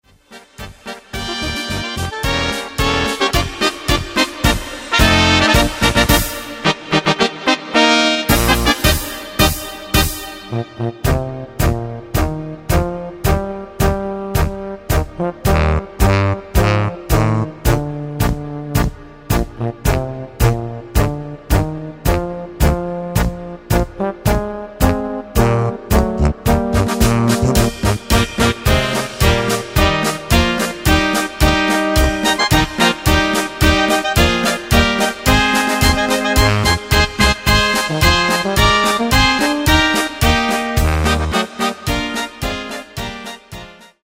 Takt:          2/4
Tempo:         109.00
Tonart:            Bb
Polka Blasmusik!